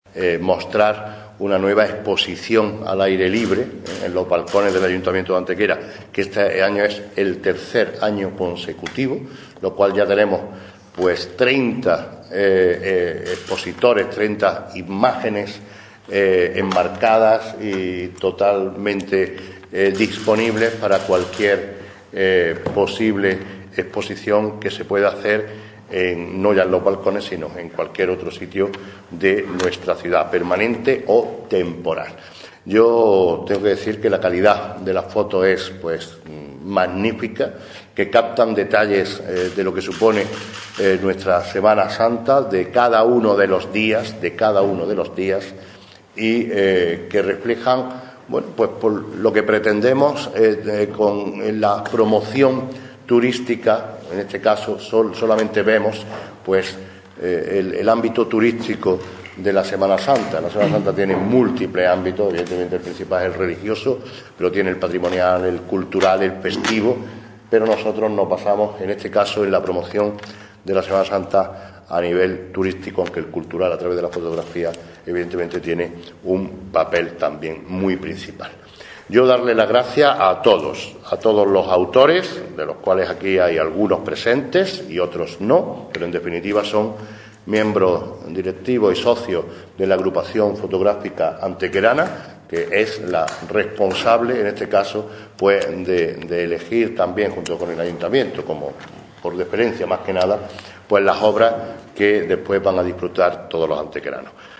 Cortes de voz M. Barón 819